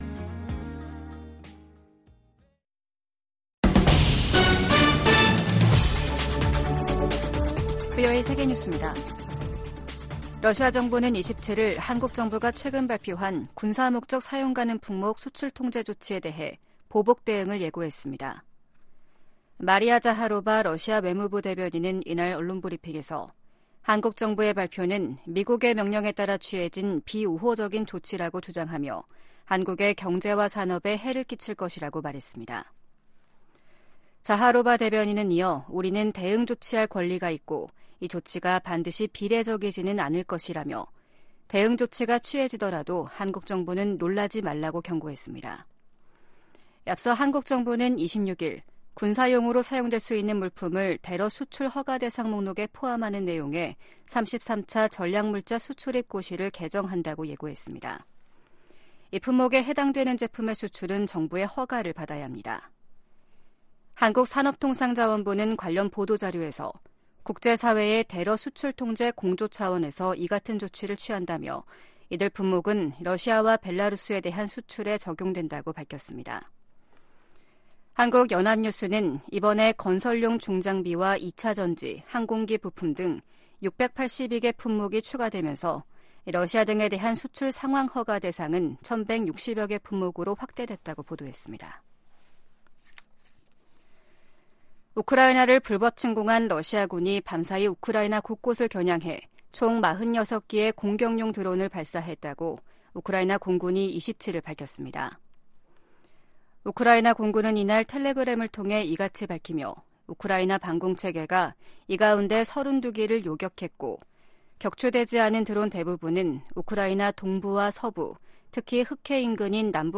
VOA 한국어 아침 뉴스 프로그램 '워싱턴 뉴스 광장’ 2023년 12월 28일 방송입니다. 팔레스타인 무장정파 하마스가 북한산 무기를 다량으로 들여왔다고 이스라엘 방위군(IDF) 대변인이 확인했습니다. 올해 미국 의회에서 발의된 한반도 외교안보 관련 법안 가운데 최종 처리된 안건은 12%에 불과했습니다. 북한인권법은 올해도 연장되지 못했습니다.